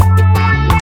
Classic reggae music with that skank bounce reggae feeling.
WAV Sample Rate: 16-Bit stereo, 44.1 kHz
Tempo (BPM): 85